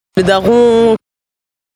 Daron download ❧ uitspraak le daron ❧ uitleg La daronne, le daron qui veut dire papa maman.
daron_prononciation.mp3